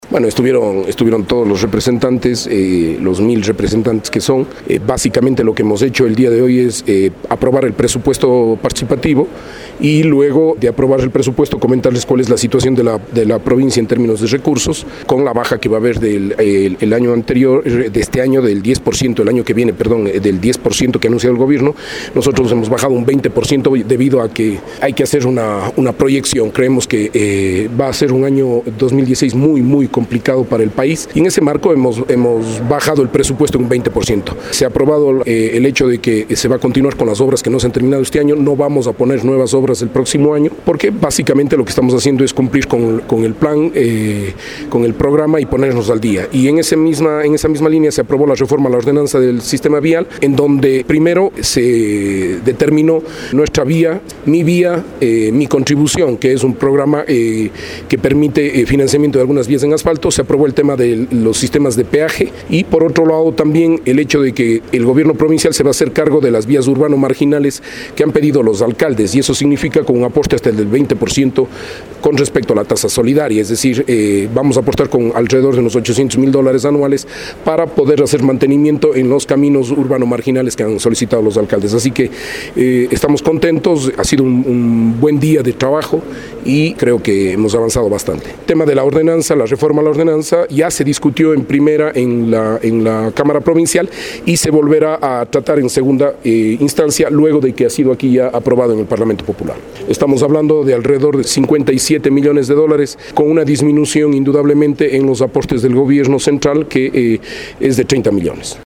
Paúl-Carrasco-prefecto-se-pronunció-sobre-el-parlamentoy-los-puntos-tratados.mp3